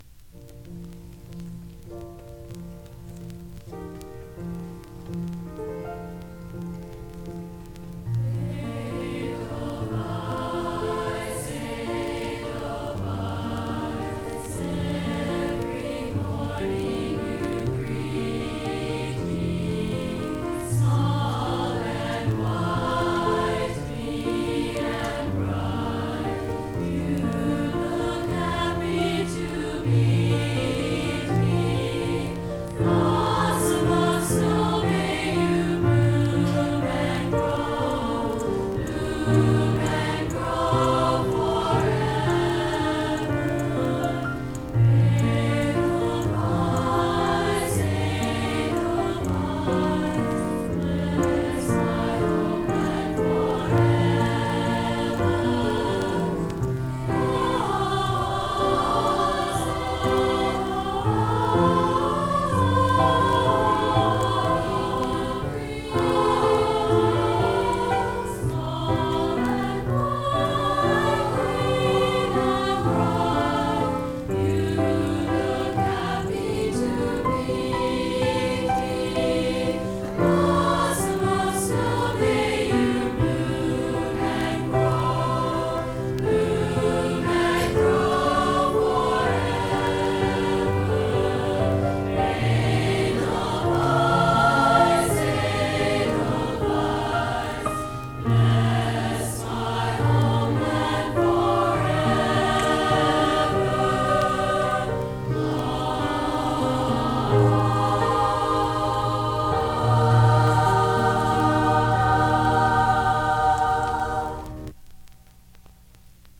“Edelweiss” from HCI Music Night 1975 by Girls’ Choir.